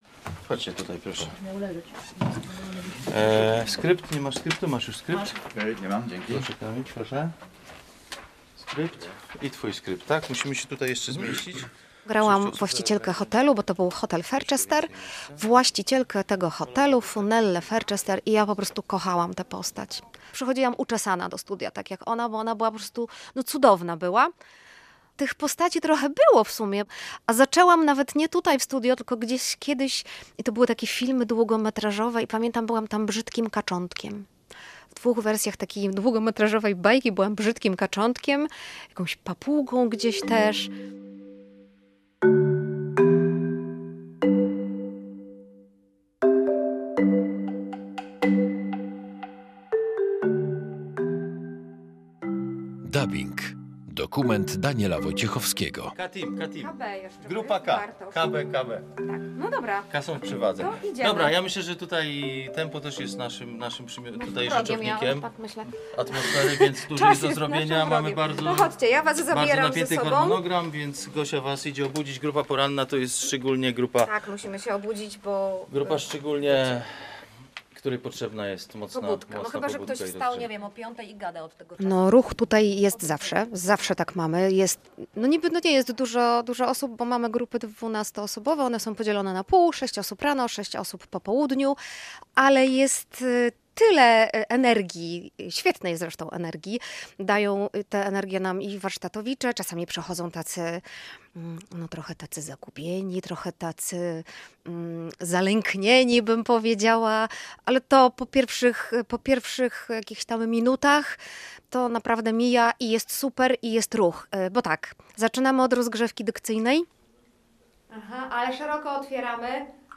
Posłuchaj dokumentu radiowego „Dubbing”: